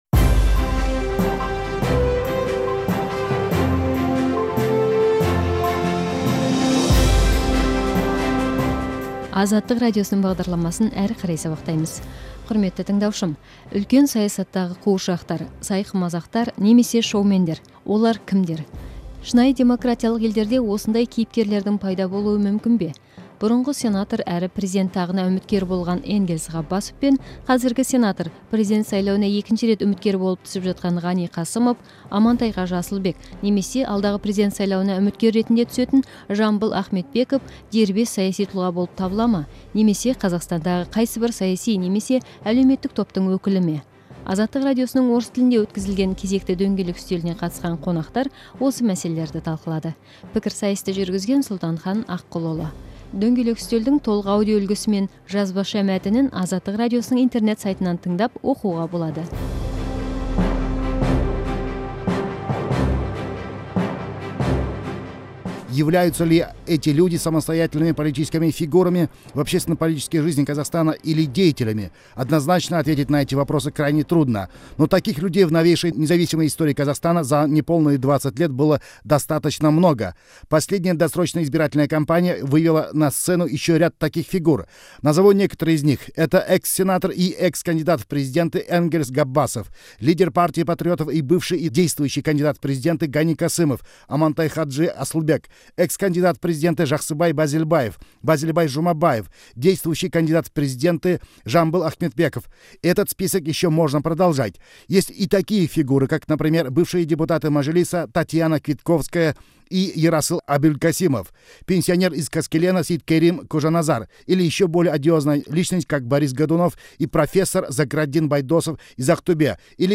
Дөңгелек үстел сұқбатын тыңдаңыз